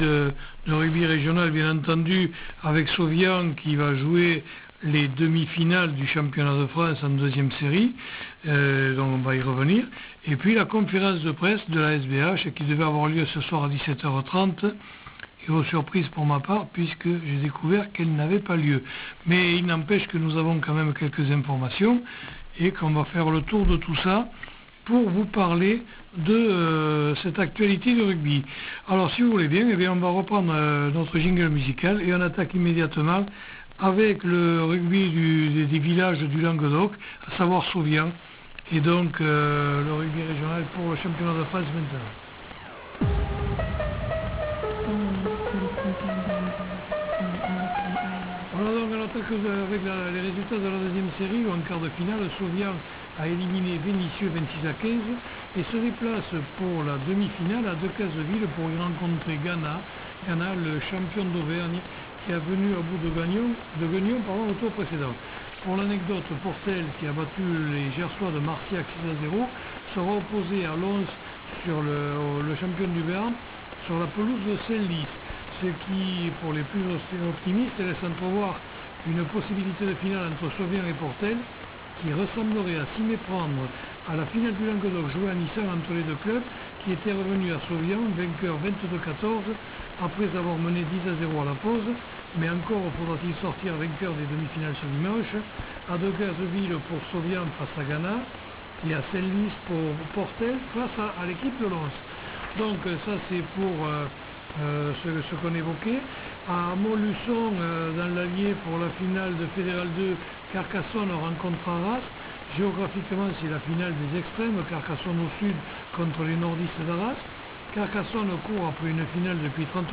PS : c'est une archive , je le dis au cas ou !!! http